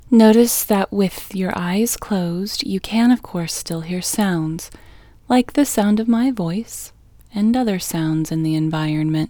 LOCATE Short OUT English Female 2